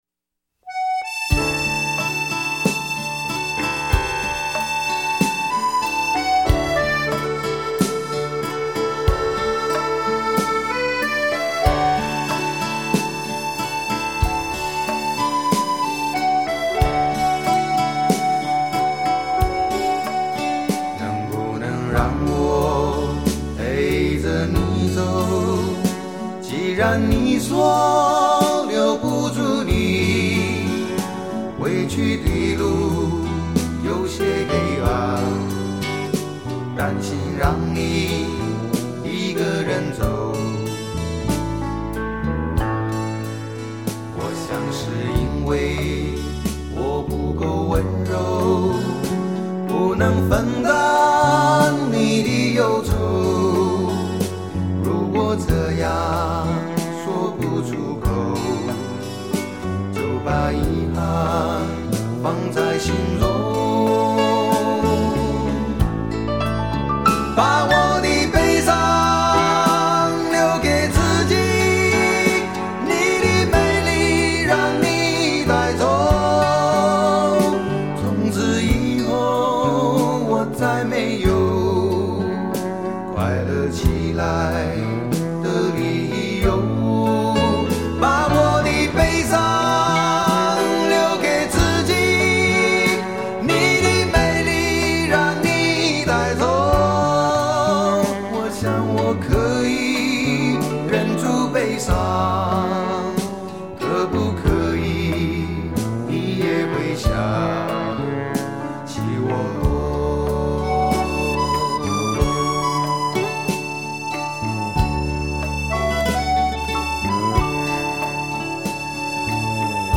随口吟唱式的旋律，伴奏乐器口琴所散发出的浓浓民谣味